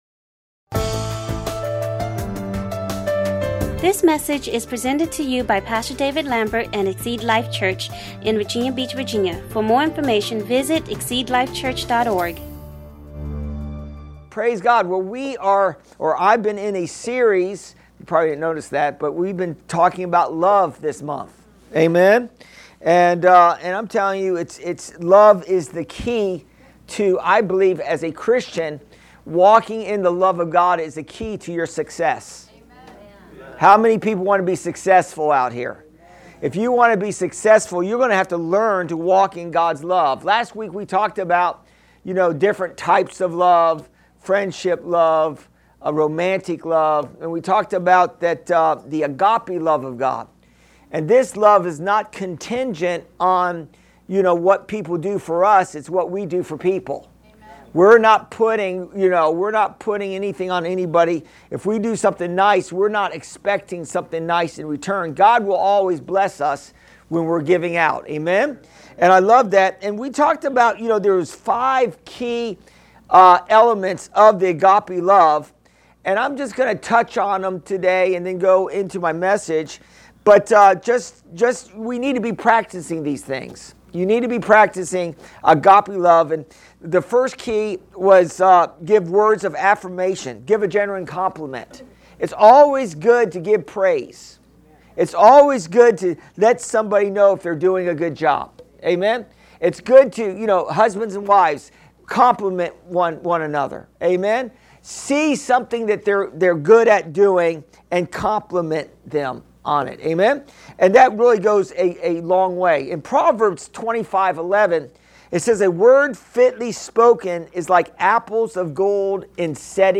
Exceed Life Church current sermon.